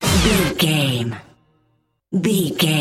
Fast paced
Ionian/Major
D
Fast
synthesiser
drum machine